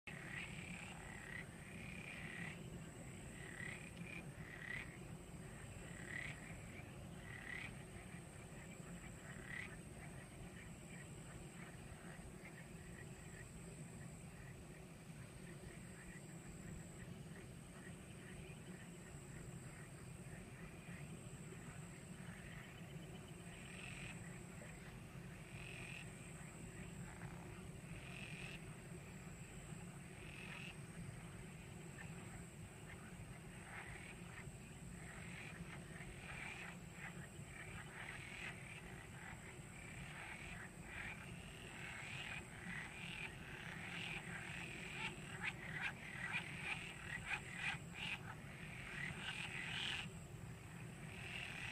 Concerto notturno in risaia
Sottofondo-notte-in-risaia.mp3